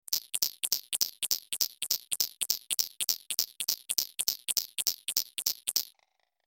جلوه های صوتی
دانلود صدای ساعت 11 از ساعد نیوز با لینک مستقیم و کیفیت بالا